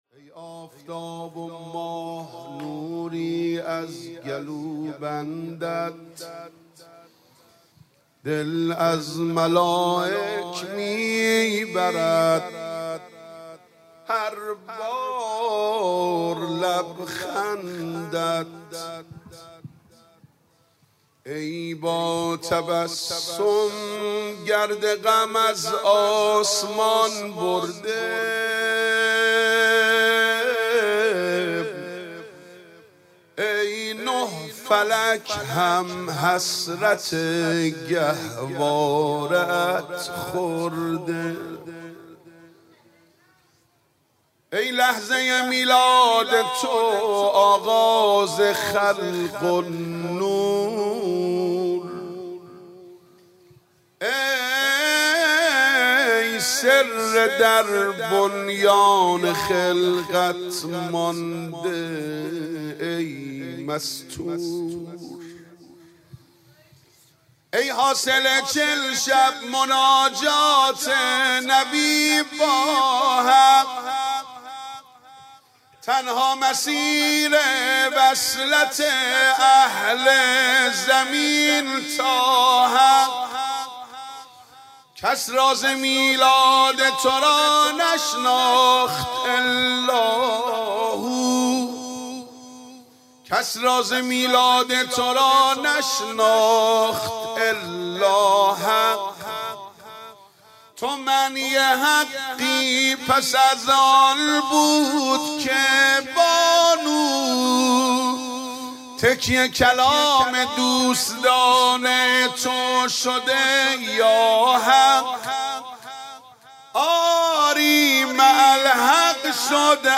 عنوان شب میلاد حضرت زهرا ۱۳۹۹ – بابل
مدح